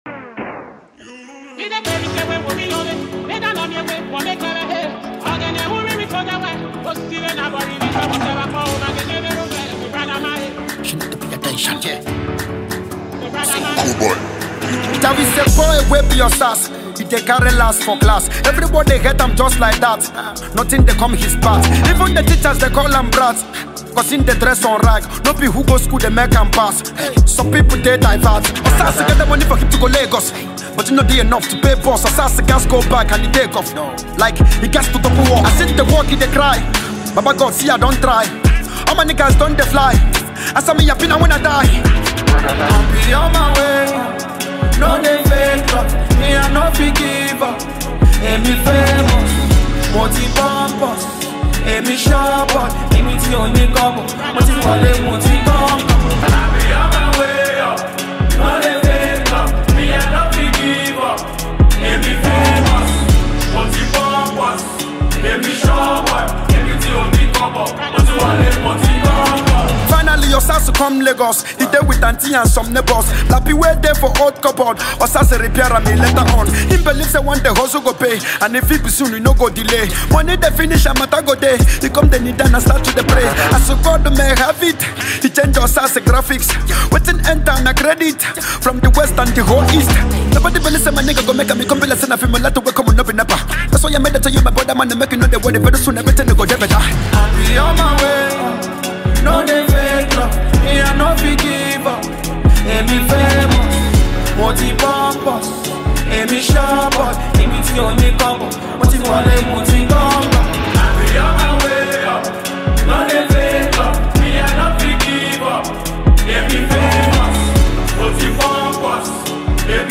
electrifying single